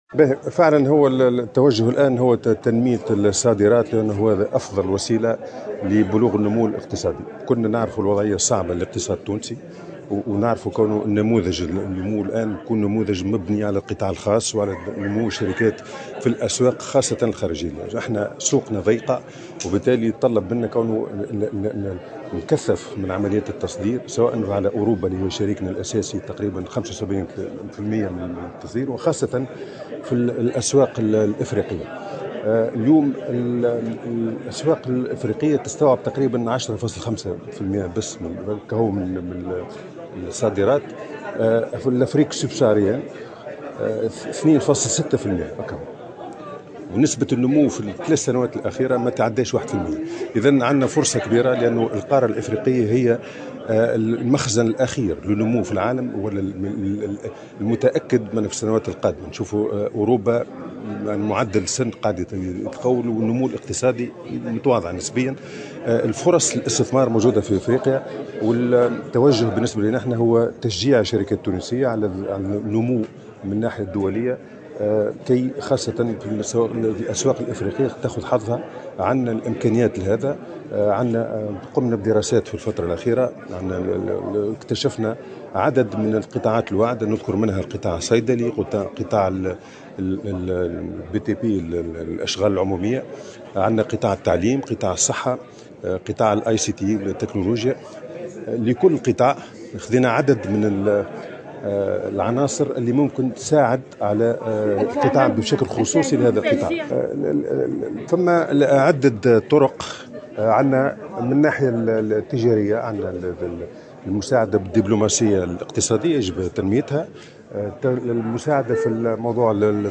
وأكد وزير الاقتصاد، في تصريح لمراسلة الجوهرة أف أم، على هامش ندوة عقدت اليوم السبت في أحد نزل العاصمة، أن تونس تعمل كذلك على إجراء جملة من الإصلاحات الهيكلية للاقتصاد الوطني من أجل إعادة إنعاشه، والتي ستشمل المؤسسات العمومية والوظيفة العمومية ومنظومة الدعم من أجل توجيهه لمستحقيه، بالإضافة إلى مراجعة الجباية وإدماج القطاع الموازي.